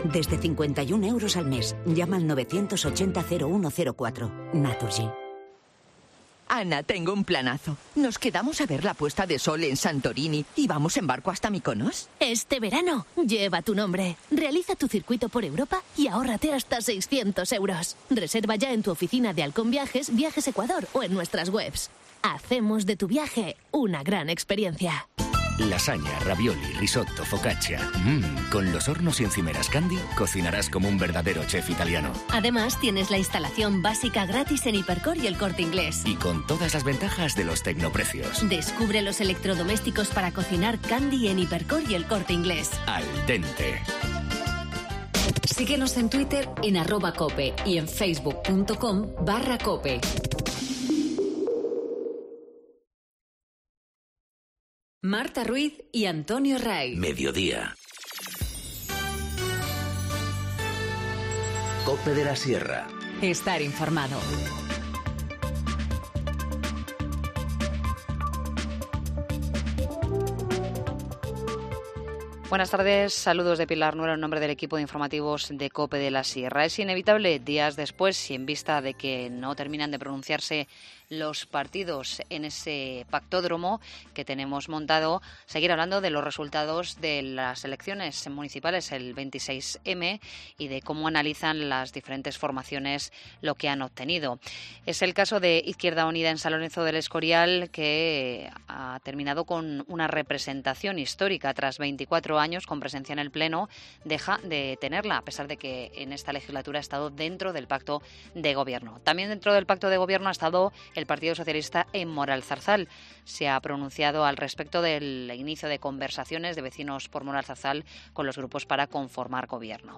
Informativo Mediodía 6 junio 14:20h